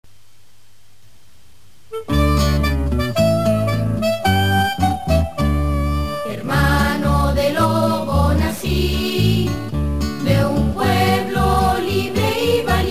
(no está completo y está cantado un poquito distinto, pero es para que tengan la idea)